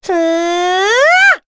Chrisha-Vox_Casting4.wav